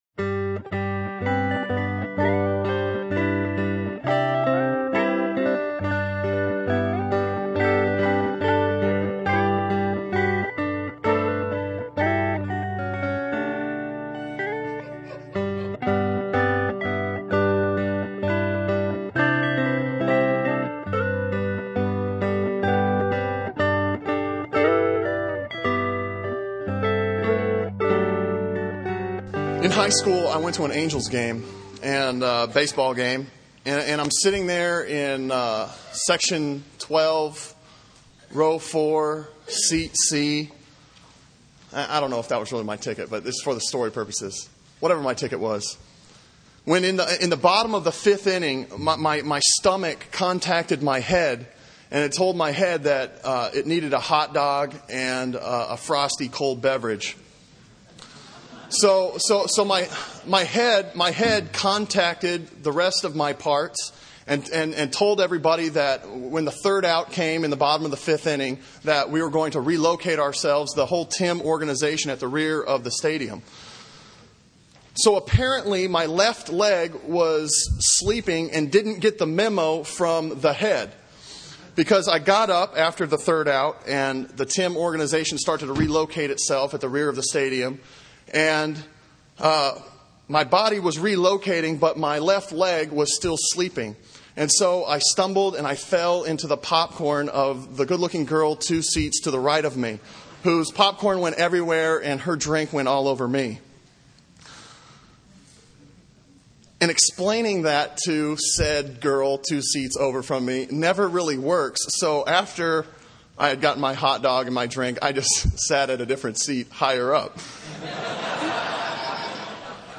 Sermon Audio from Sunday
Sermon on Ephesians 4:7-16 from November 11